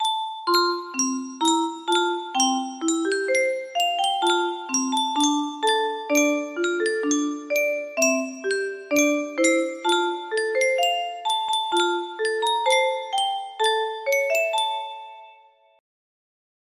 Yunsheng Music Box - Unknown Tune 1073 music box melody
Full range 60